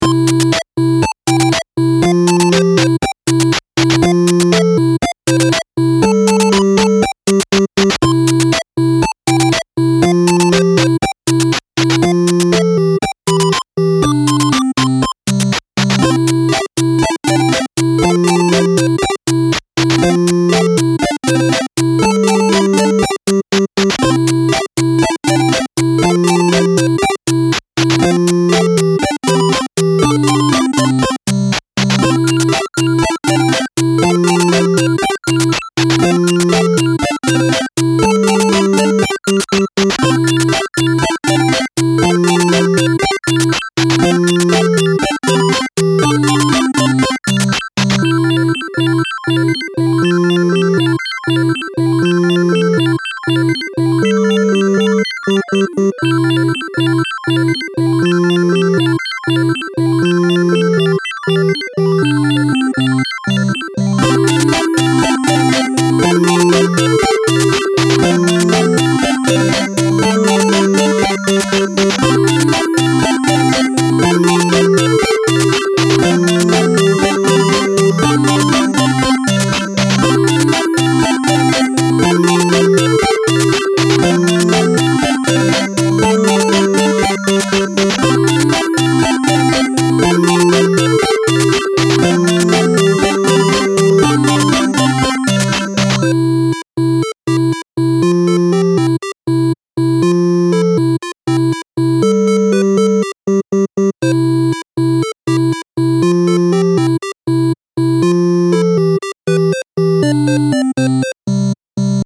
Style Style Chiptune
Mood Mood Bouncy, Bright
Featured Featured Synth
BPM BPM 120